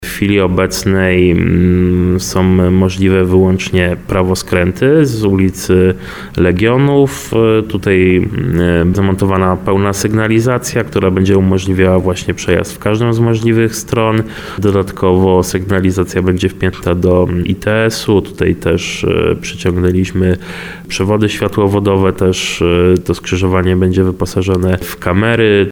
Ma to znacznie ułatwić życie kierowcom, którzy próbują wydostać się z ul. Legionów na ul. Mickiewicza – mówi wiceprezydent Tarnowa Maciej Włodek.